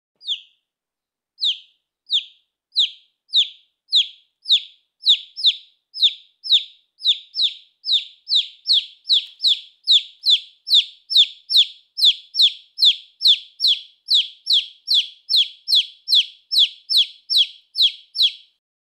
chant-du-poussin.mp3